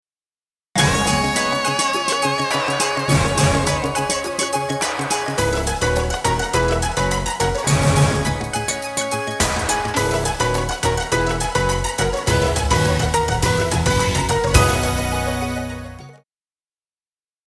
片头音乐